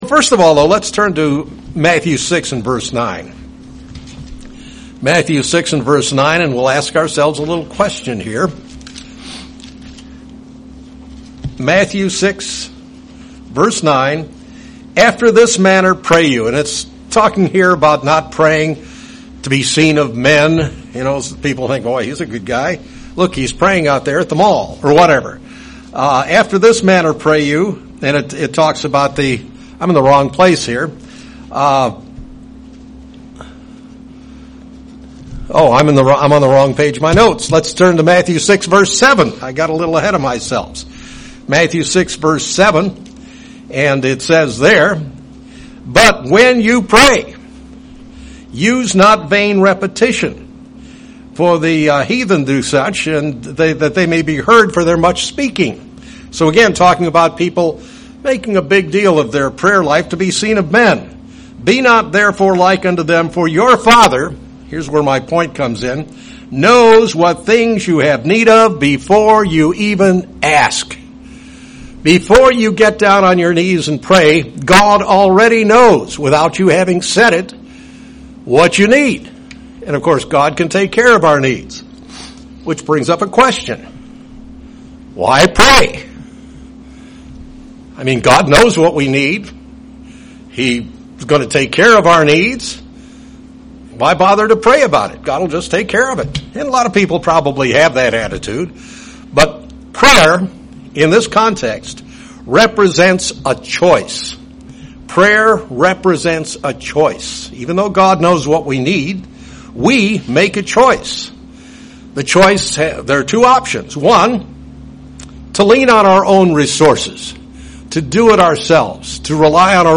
What role should prayer take in a Christians life? In this sermon, the speaker looks into the subject of prayer.